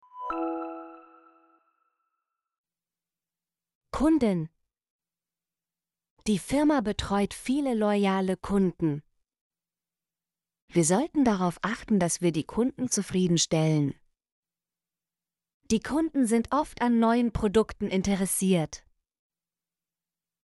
kunden - Example Sentences & Pronunciation, German Frequency List